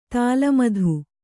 ♪ tāla madhu